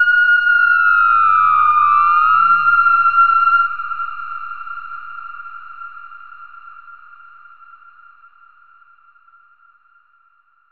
Theremin_Swoop_03.wav